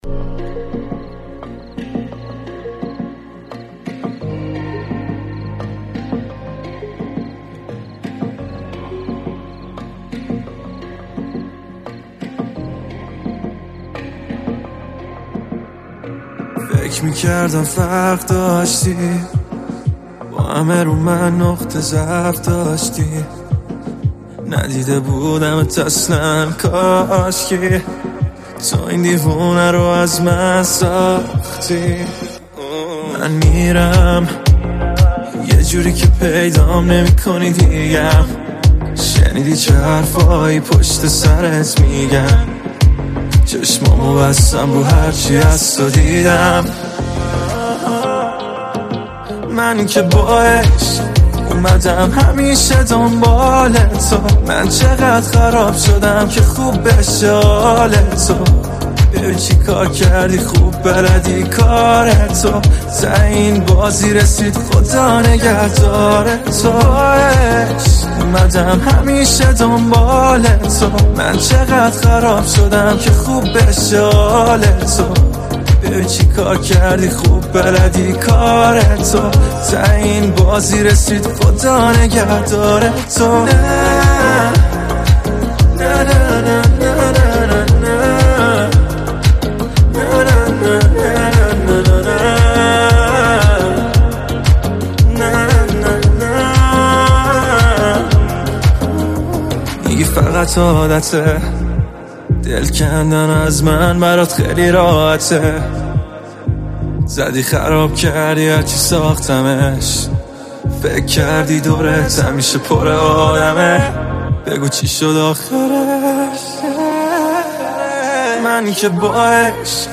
بسیار زیبا و احساسی
غمگین